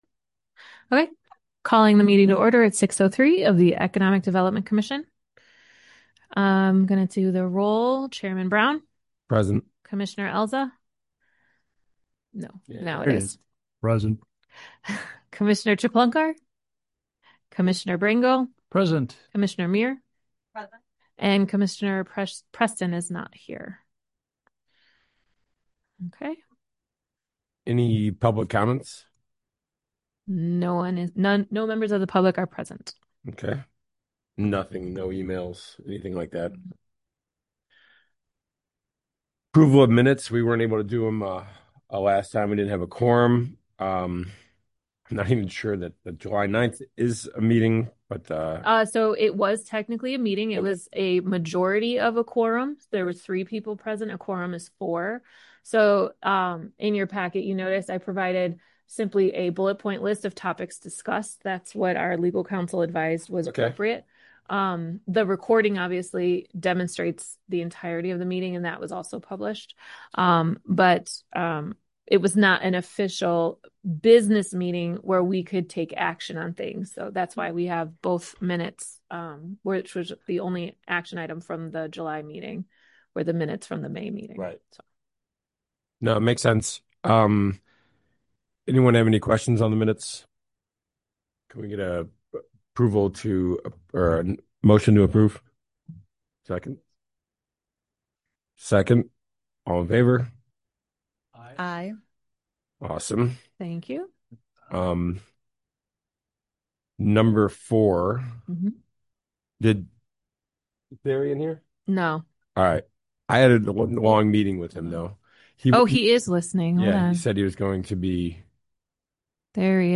Economic Development Commission Meeting